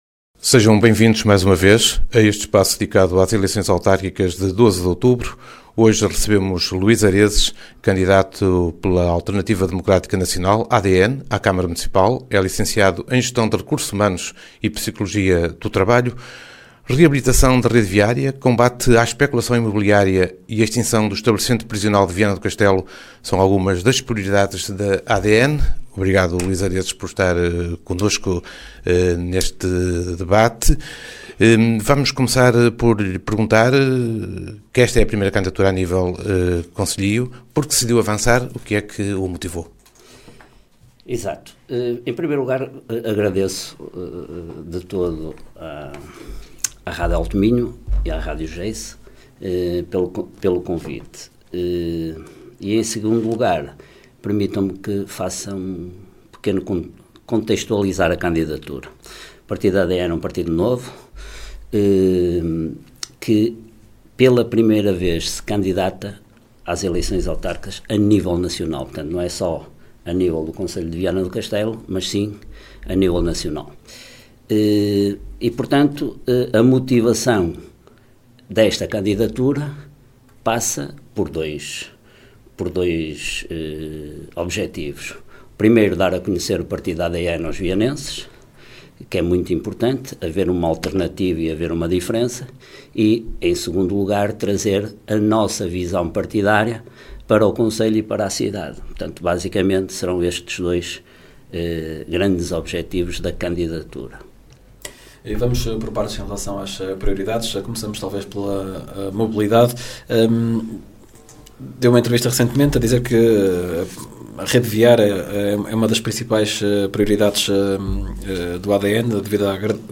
Ação conjunta da Rádio Geice FM e da Rádio Alto Minho, que visa promover um ciclo de entrevistas aos candidatos à presidência da Câmara Municipal de Viana do Castelo.